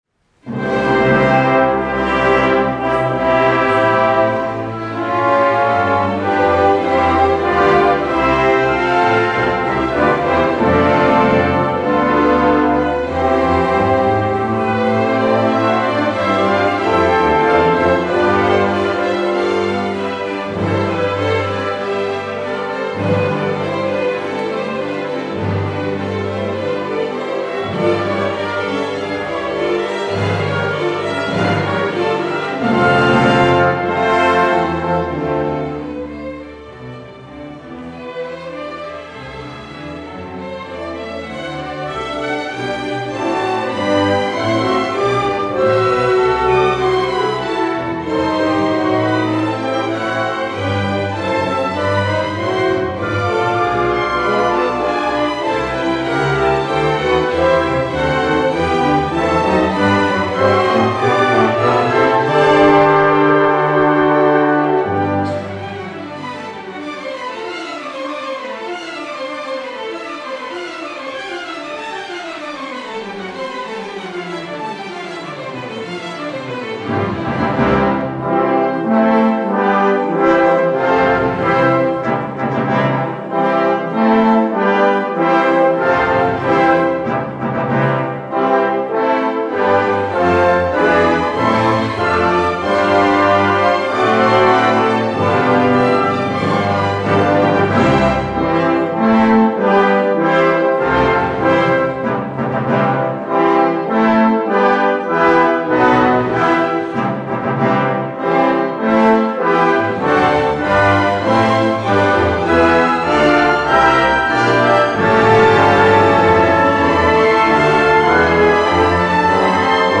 Frühjahrskonzert 2019 – Track 2 + 5 + 8 Gustav Albert Lortzing: „Fünftausend Taler“
Frühjahrskonzert-2019-Track-8.mp3